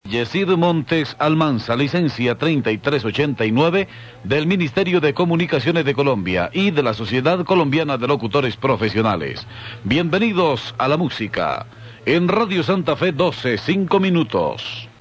Some programme hosts will make it a point to mention their license numbers when signing on or signing off.
Again, in Venezuela and in Colombia, a programme host tends to be more emphatic than the man in the street.